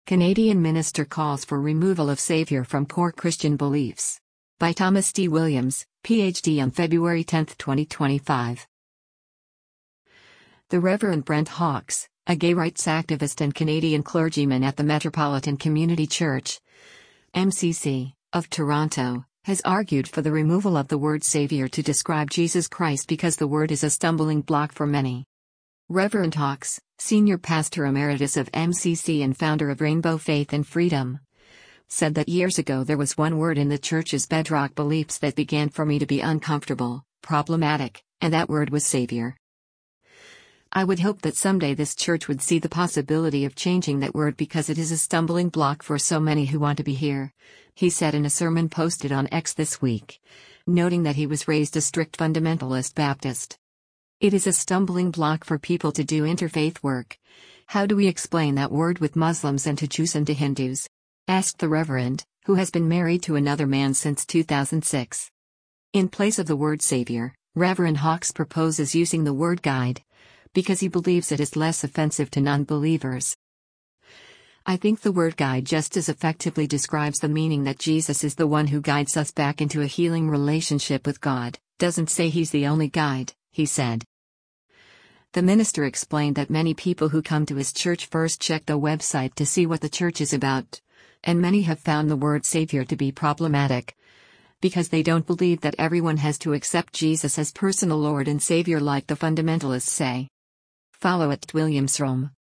“I would hope that someday this church would see the possibility of changing that word because it is a stumbling block for so many who want to be here,” he said in a sermon posted on X this week, noting that he was “raised a strict fundamentalist Baptist.”